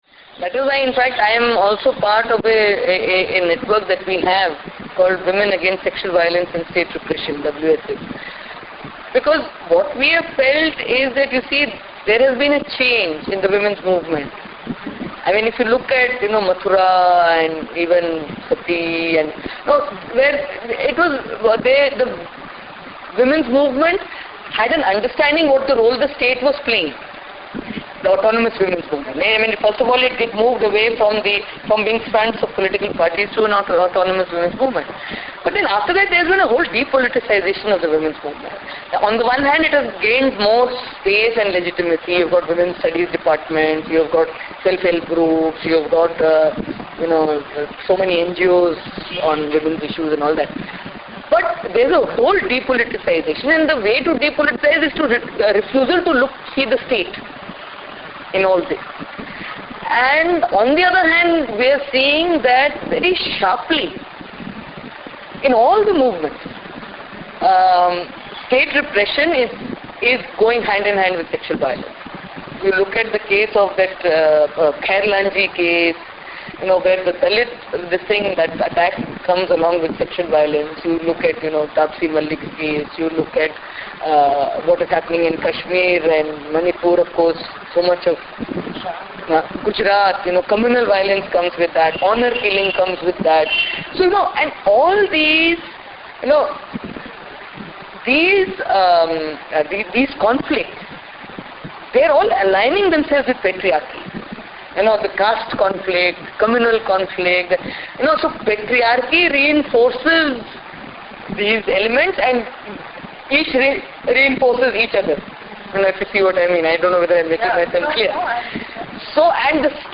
Audio Excerpt from the interview with Sudha Bharadwaj